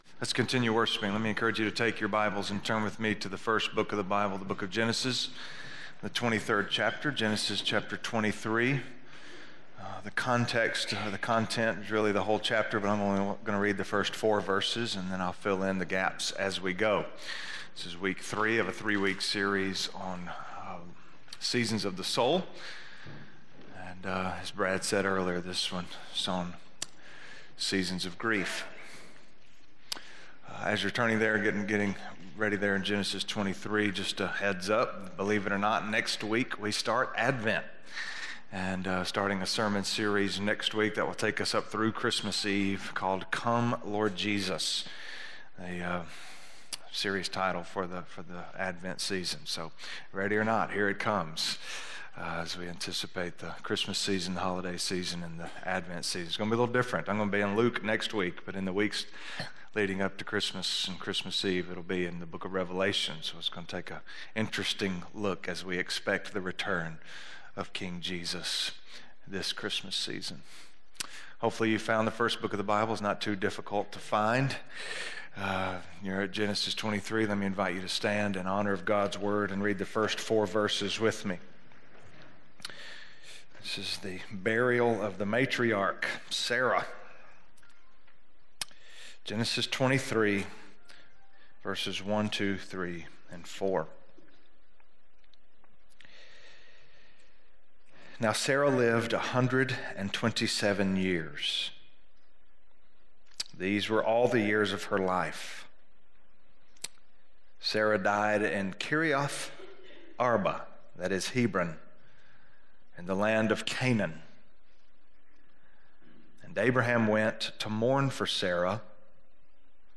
Letting Go of Grief - Sermon - West Franklin